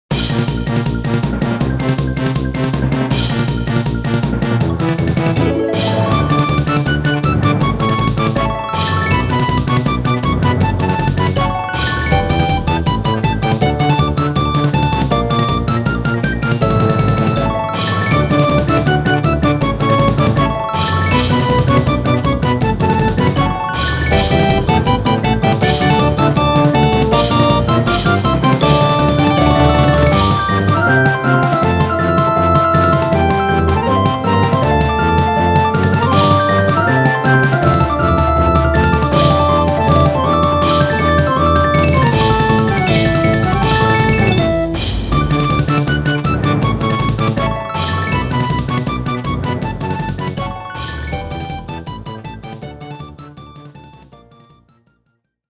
これらの曲は、全てドリームキャストを使って作りました。